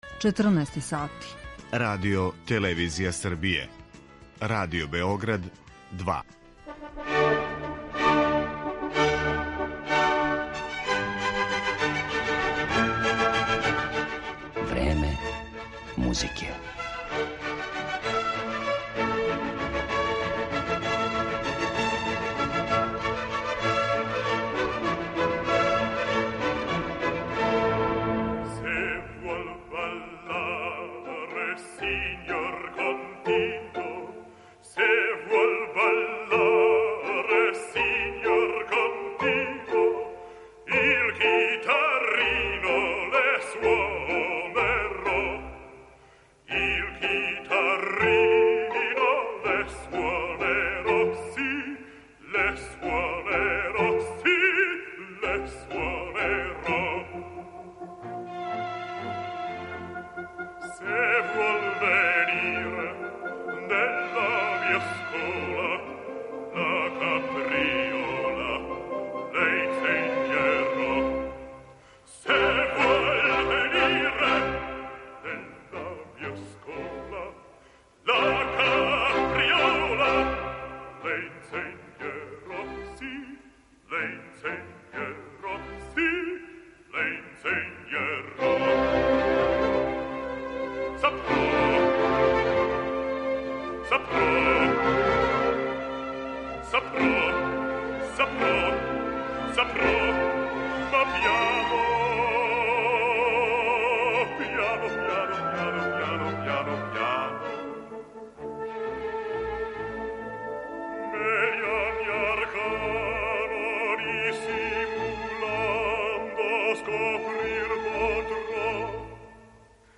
На мелодију арије из опере Фигарова женидба, на пример, Лудвиг ван Бетовен је компоновао варијације за виолину и клавир.